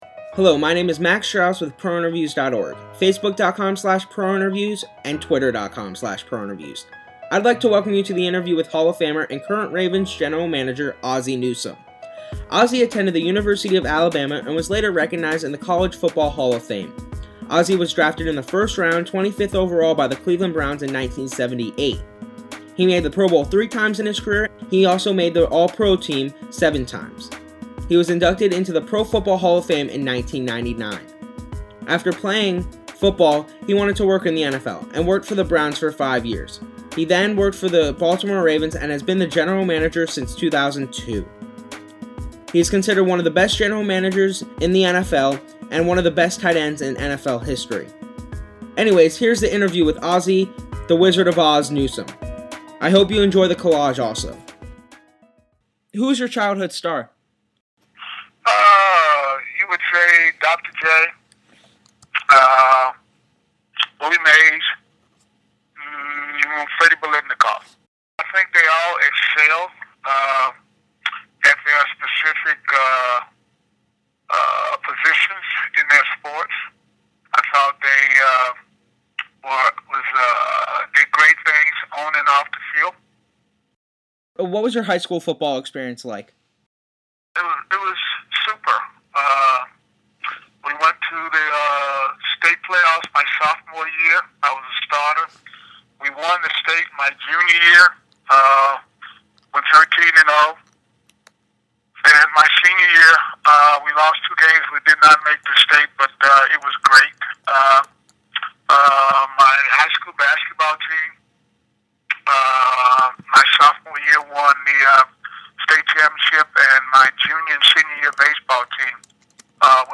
Hall of Fame Tight End, Current Ravens General Manager, Ozzie Newsome Interview
interview-with-ozzie-newsome.mp3